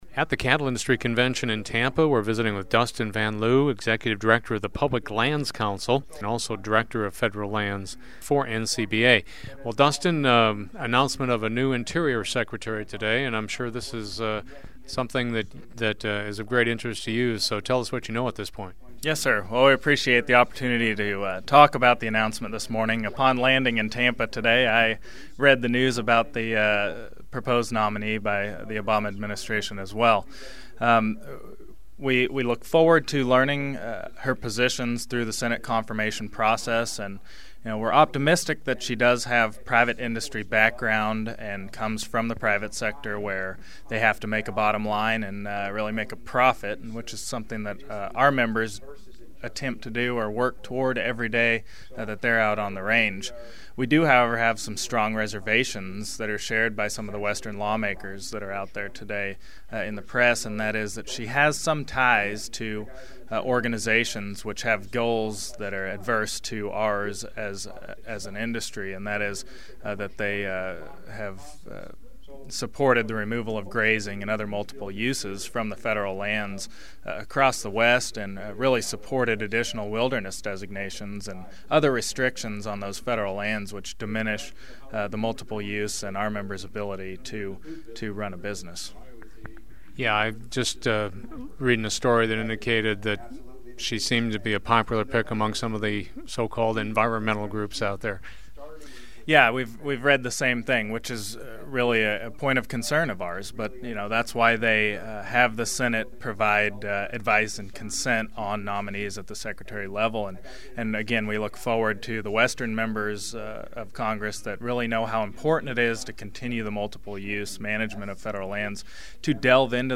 Speaking with Brownfield at the Cattle Industry Convention in Tampa